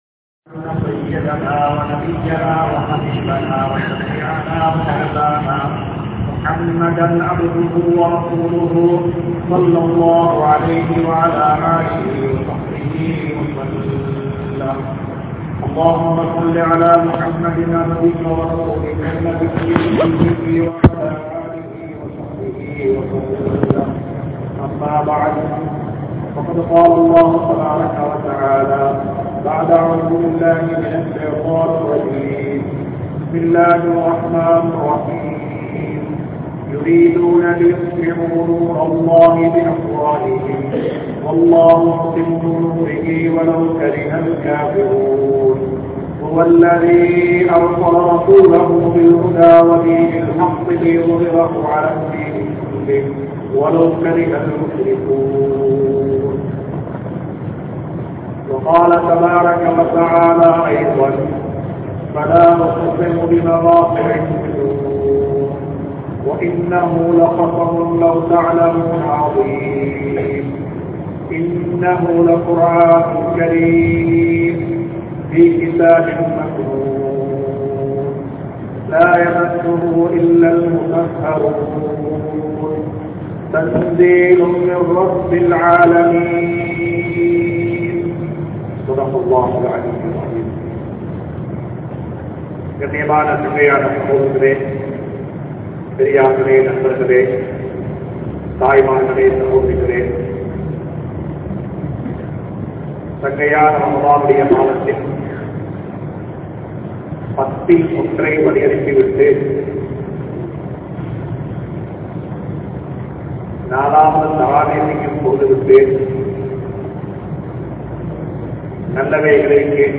Vaalkaiyai Alikkum Paavangal (வாழ்க்கையை அழிக்கும் பாவங்கள்) | Audio Bayans | All Ceylon Muslim Youth Community | Addalaichenai
Katugasthoata Jumua Masjith